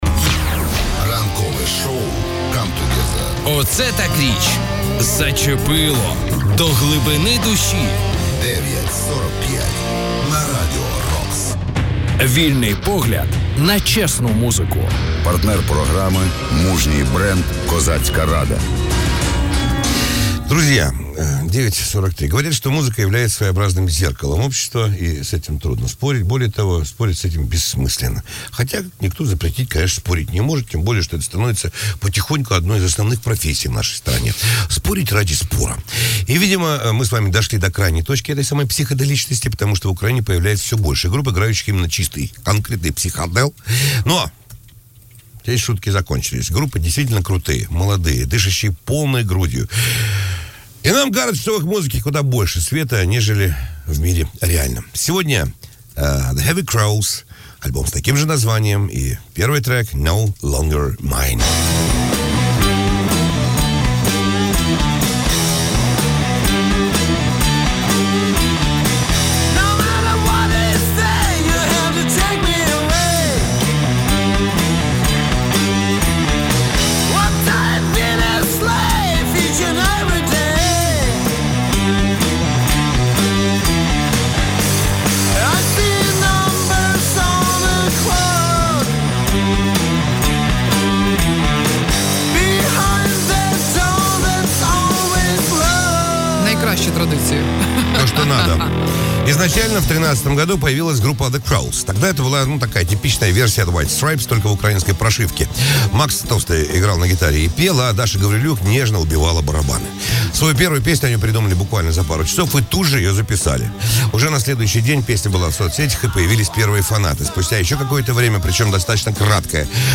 А не - хорошо, что не под фанеру